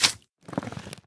Minecraft Version Minecraft Version snapshot Latest Release | Latest Snapshot snapshot / assets / minecraft / sounds / mob / magmacube / jump2.ogg Compare With Compare With Latest Release | Latest Snapshot
jump2.ogg